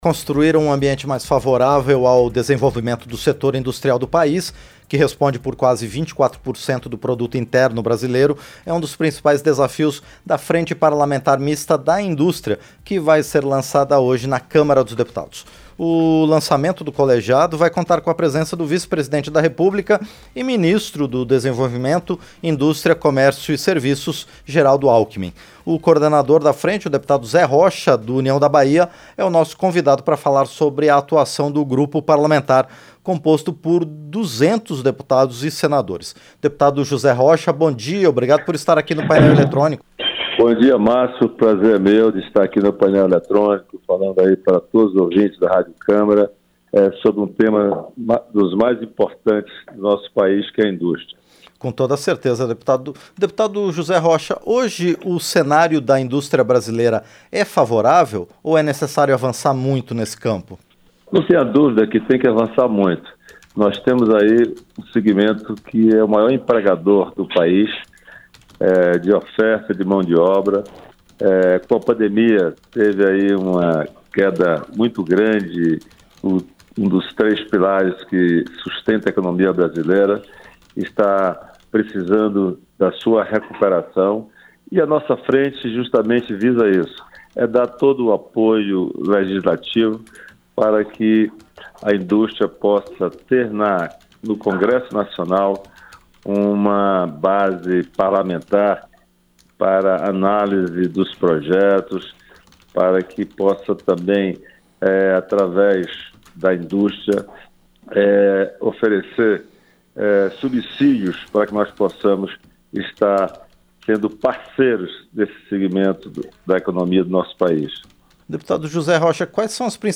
Entrevista - Dep. José Rocha (União-PE)